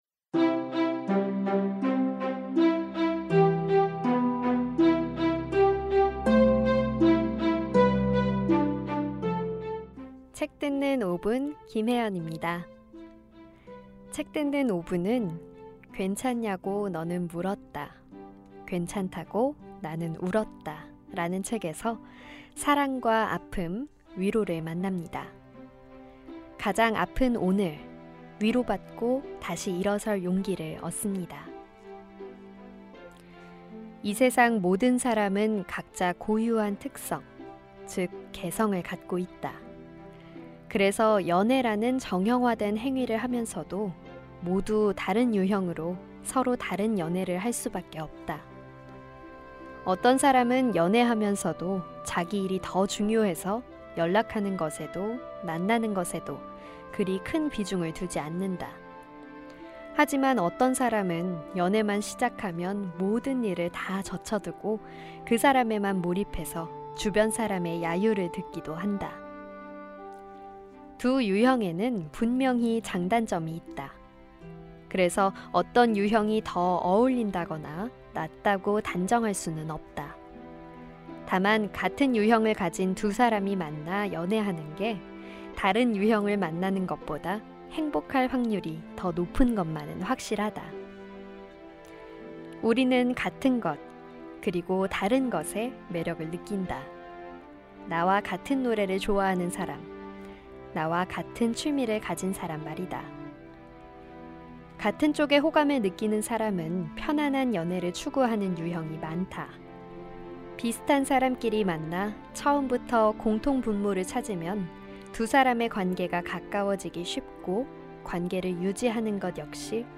<책 듣는 5분>은 <괜찮냐고 너는 물었다, 괜찮다고 나는 울었다>라는 책에서 사랑과 아픔, 위로를 만납니다.